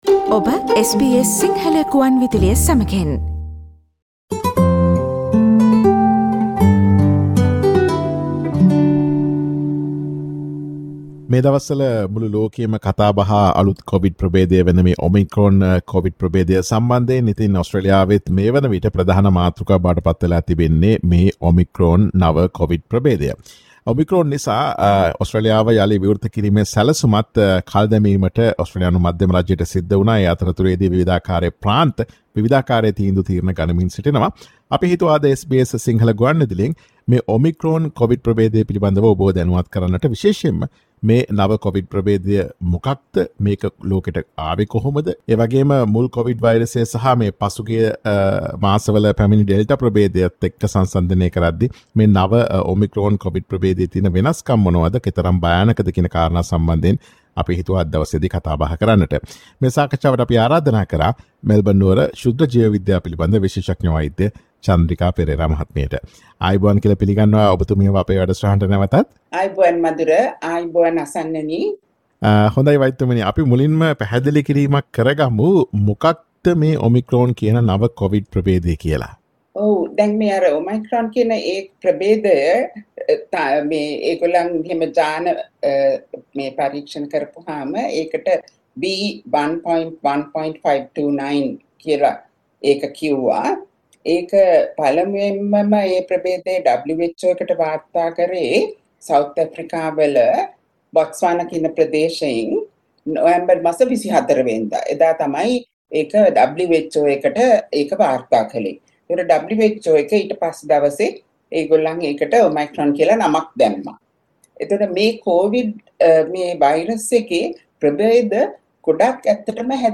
කොවිඩ් වයිරසයේ නවතම ප්‍රභේදය වන Omicron ප්‍රභේදය මුල් කොවිඩ් වයිරසයට සහ Delta ප්‍රභේදයට සාපේක්ෂව කෙතරම් භයානකදැයි SBS සිංහල ගුවන් විදුලිය සිදුකළ සාකච්ඡාවට සවන් දීමට ඉහත ඡායාරූපය මත ඇති speaker සලකුණ මත click කරන්න.